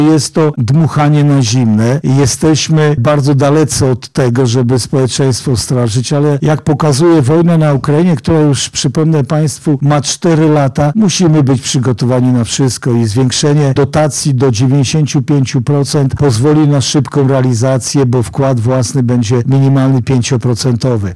Stawiarski 2 – dodaje Jarosław Stawiarski